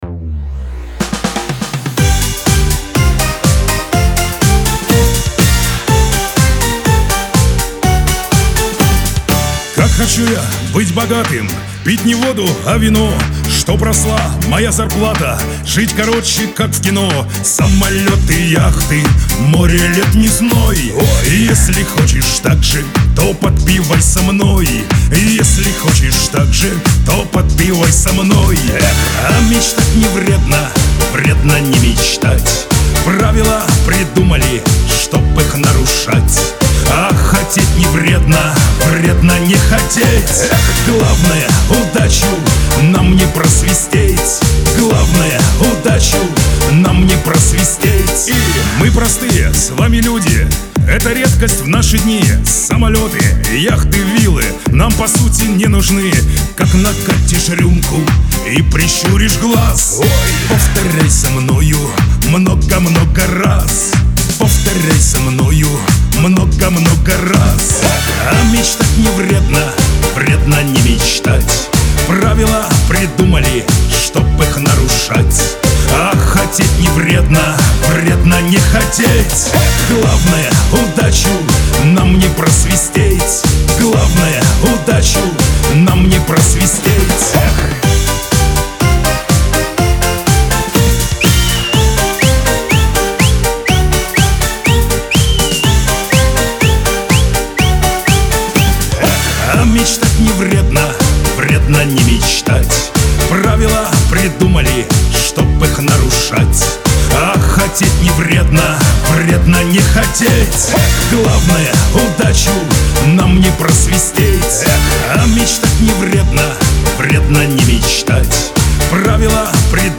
Веселая музыка
Лирика , pop